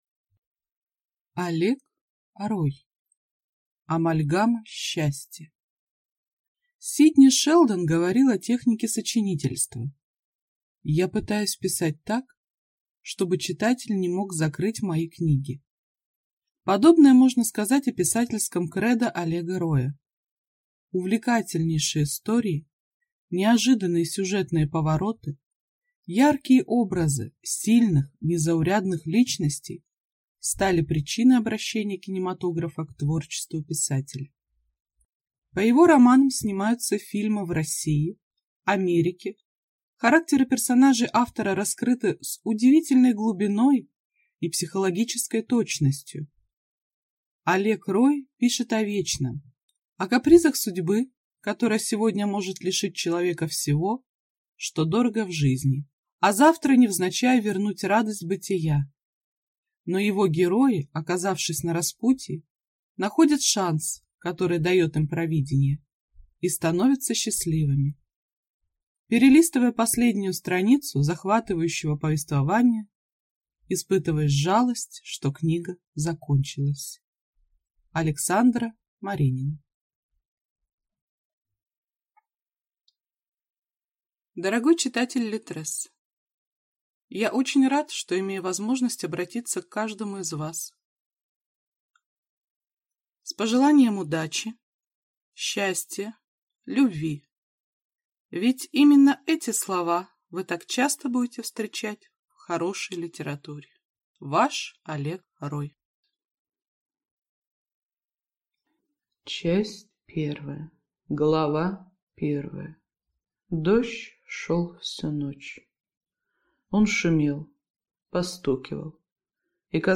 Аудиокнига Амальгама счастья | Библиотека аудиокниг